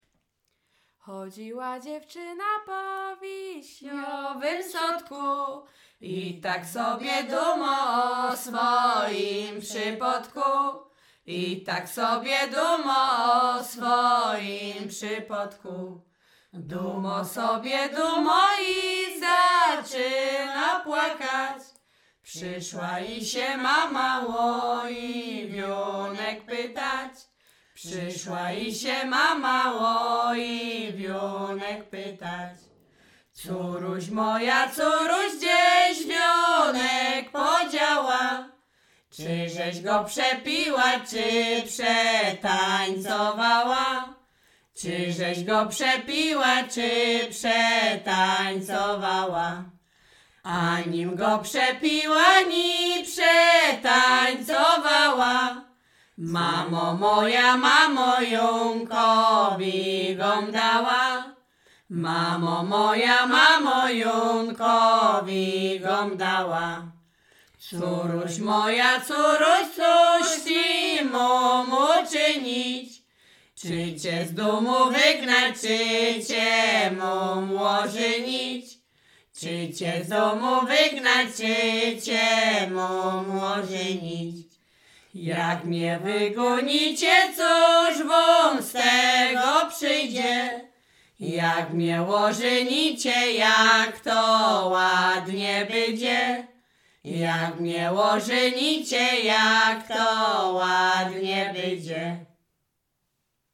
liryczne miłosne weselne